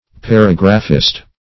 Paragraphist \Par"a*graph`ist\, n.